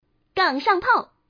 Index of /client/common_mahjong_tianjin/mahjongwuqing/update/1161/res/sfx/changsha/woman/